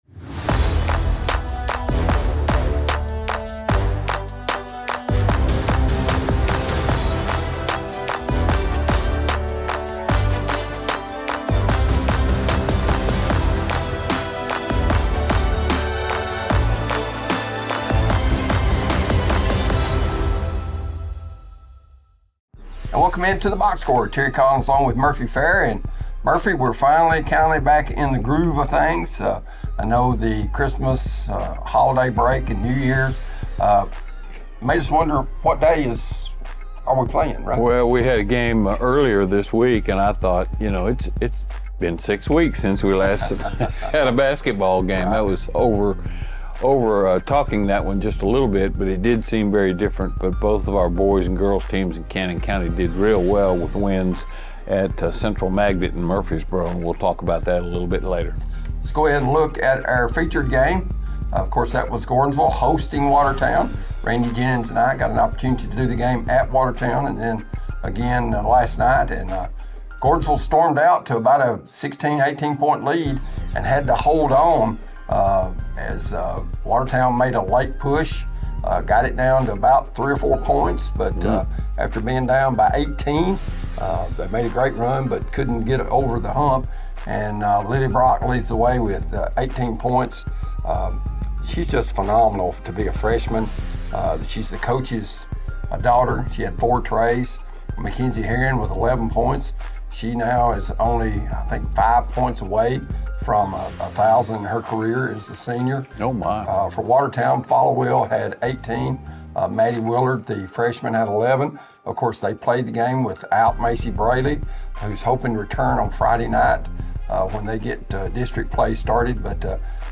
Join us each Thursday throughout high school football and basketball seasons for The Box Score, DTC Sports’ weekly sports talk show featuring highlights, scores, analysis, and game previews.